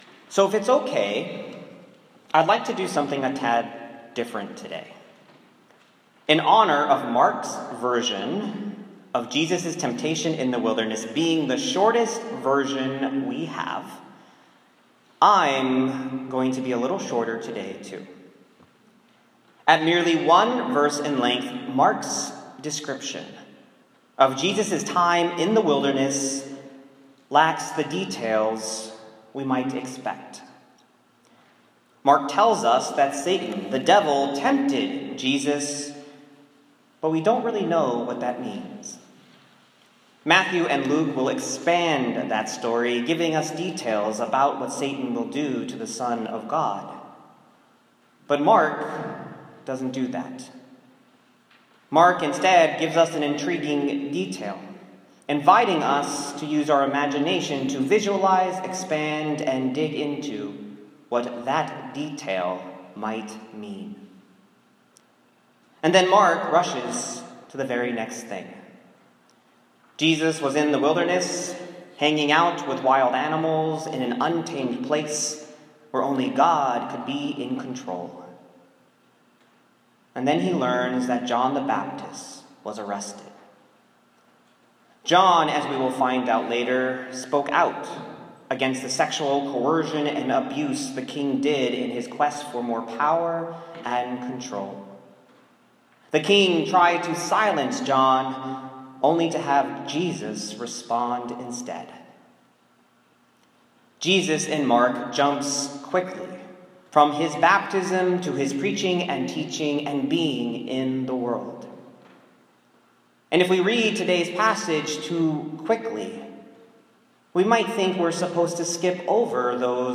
Mark 1:9-15 My sermon from First Sunday in Lent (February 18, 2018) on Mark 1:9-15.